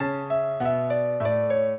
minuet5-10.wav